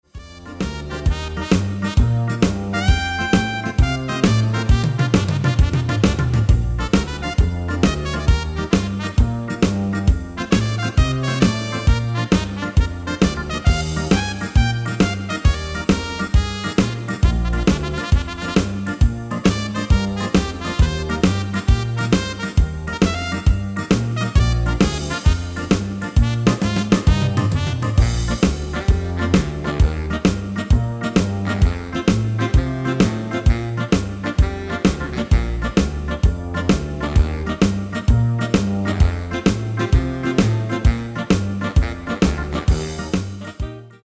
Demo/Koop midifile
Genre: Evergreens & oldies
Toonsoort: Bb
- GM = General Midi level 1